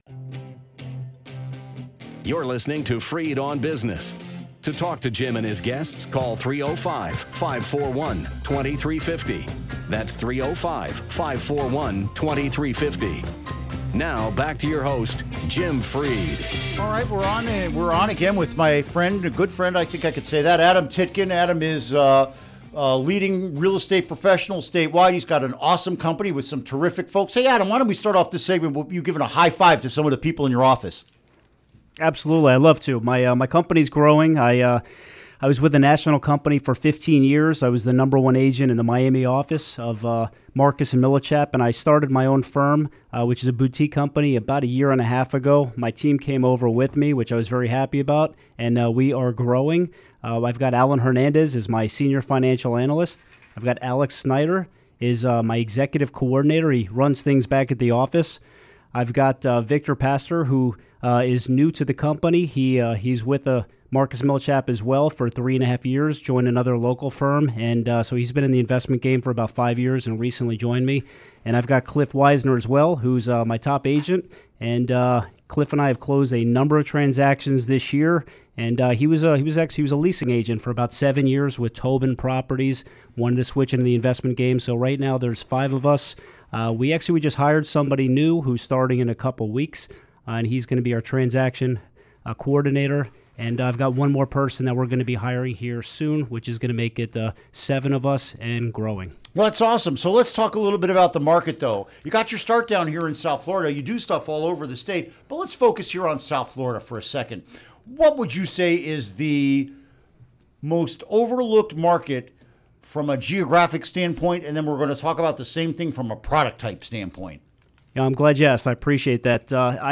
Interview Segment Click here to download Part 1 (To download, right-click and select “Save Link As”.)